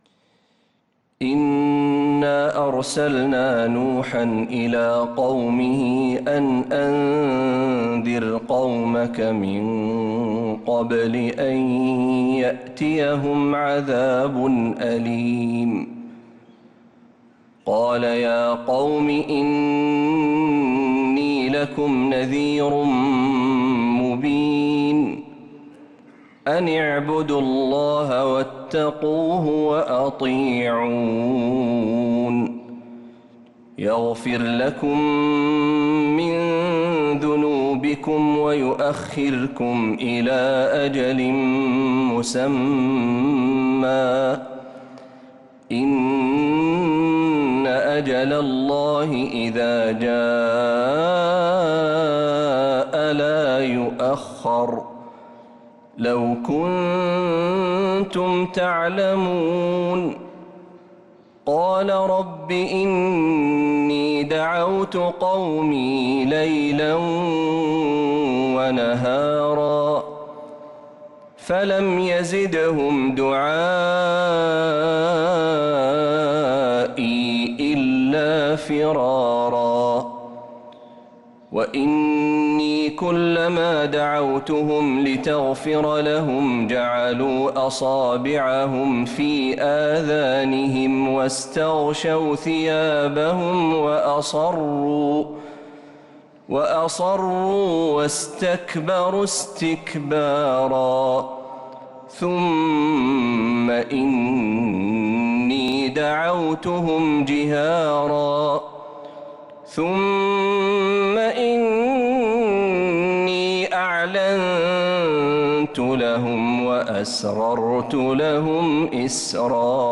سورة نوح كاملة من عشائيات الحرم النبوي للشيخ محمد برهجي | جمادى الآخرة 1446هـ > السور المكتملة للشيخ محمد برهجي من الحرم النبوي 🕌 > السور المكتملة 🕌 > المزيد - تلاوات الحرمين